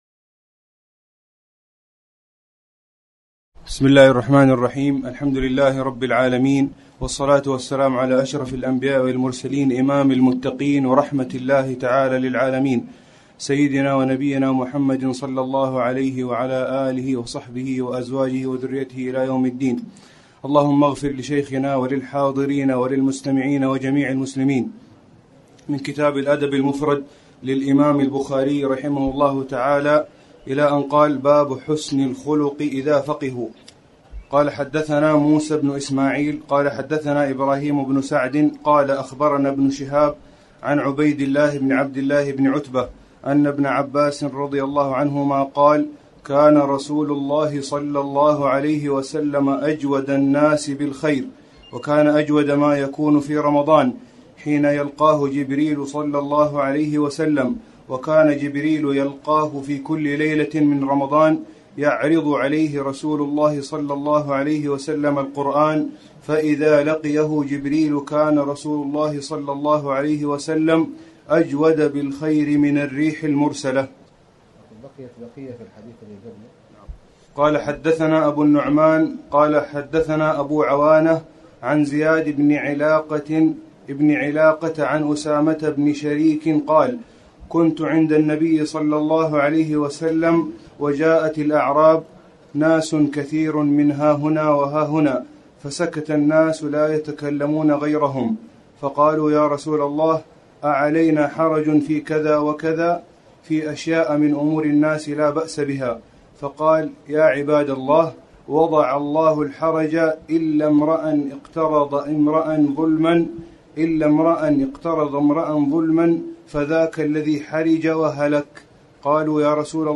تاريخ النشر ١ ذو القعدة ١٤٣٨ هـ المكان: المسجد الحرام الشيخ: خالد بن علي الغامدي خالد بن علي الغامدي حسن الخلق إذا فقهوا